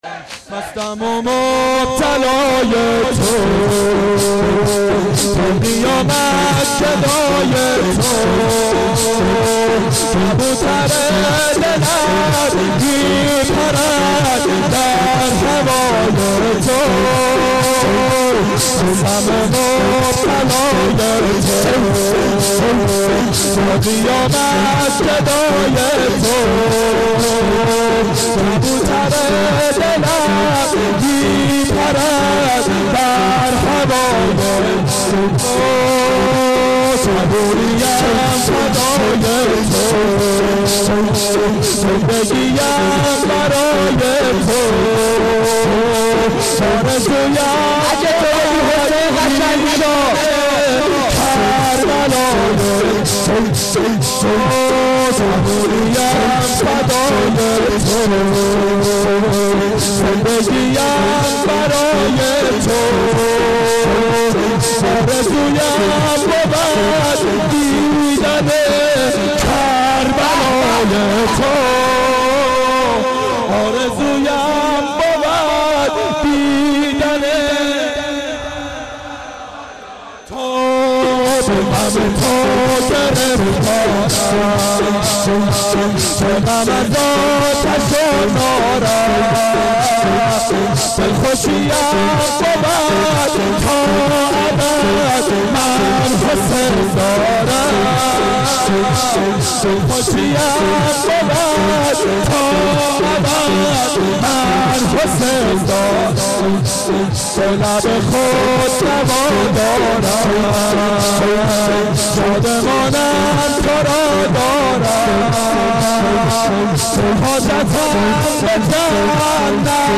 شب سوم محرم 88 گلزار شهدای شهر اژیه